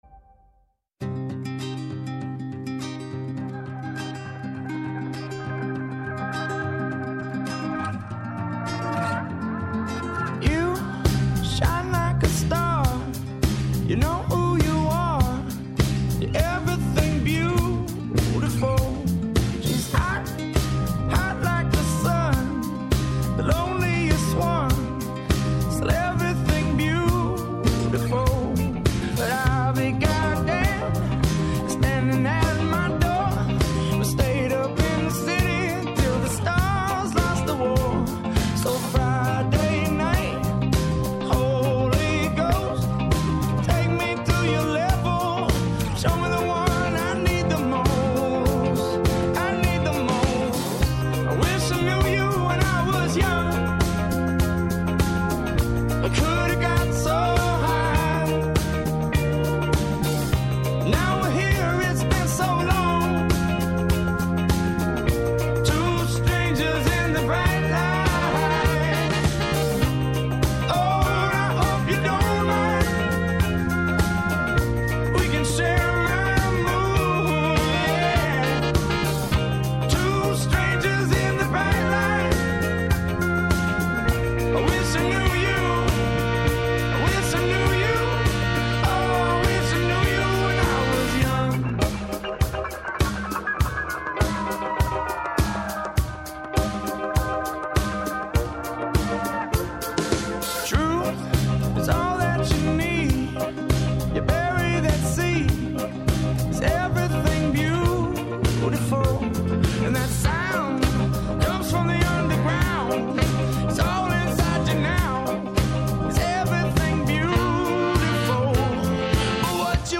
Καλεσμένη στο στούντιο η ψυχολόγος